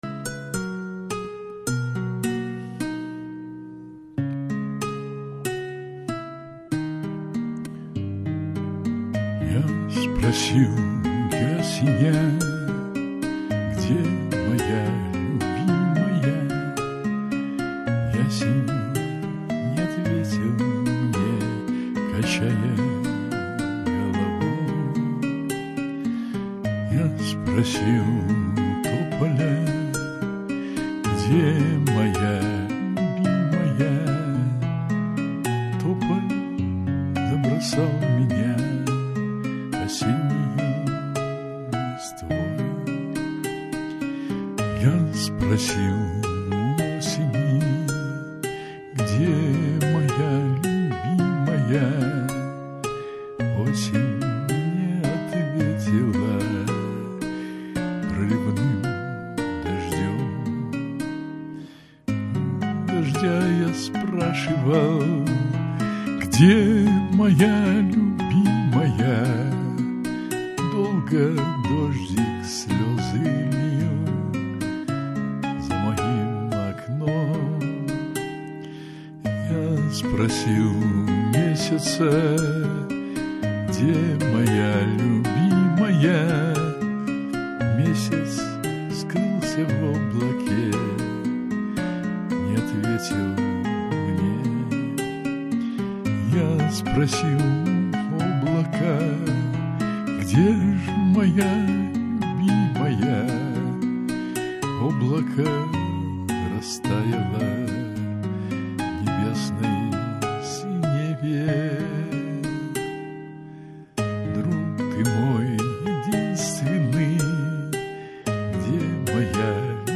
голоса слишком близко